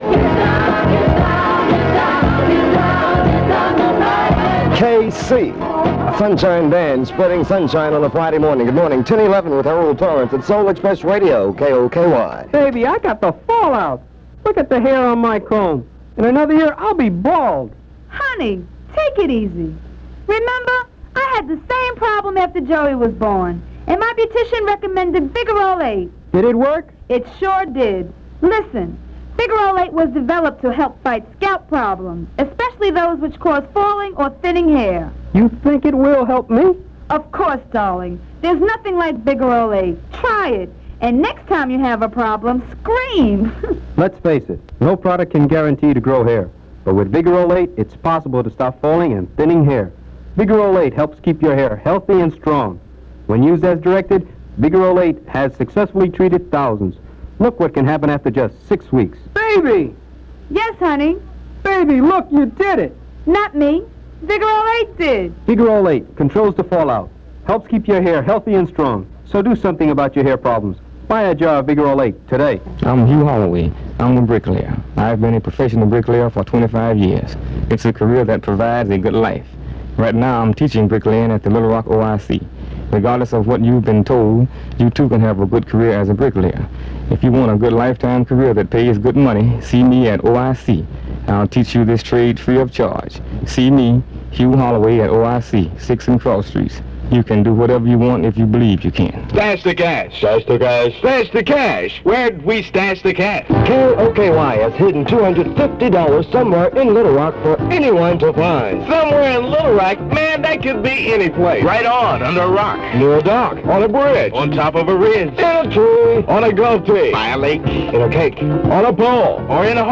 I’m excited to share audio of a newly-digitized recording of legendary Little Rock R&B station KOKY-AM 1440 on August 8, 1975.
There are lots of great jingles in between current and classic R&B hits.
Side A of the cassette ran out midway through the newscast and perhaps he gave his name at the end.
The music has been edited to only include the beginnings and ends of songs to avoid potential copyright violations.